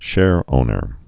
(shârōnər)